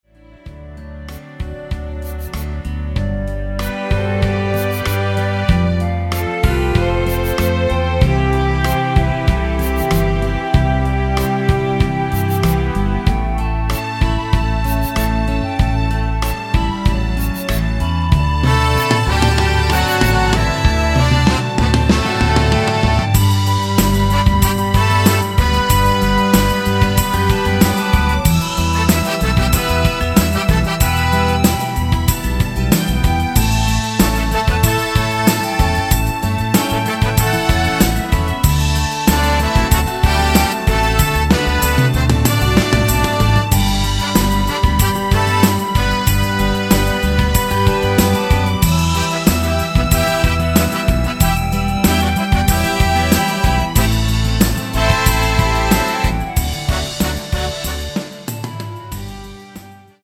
원키 멜로디 포함된 MR 입니다.(미리듣기 참조)
멜로디 MR이라고 합니다.
앞부분30초, 뒷부분30초씩 편집해서 올려 드리고 있습니다.
중간에 음이 끈어지고 다시 나오는 이유는